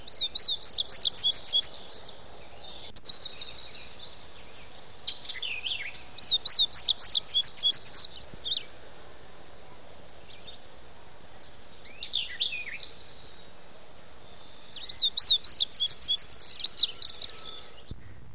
Łuszczyk indygo - Passerina cyanea
głosy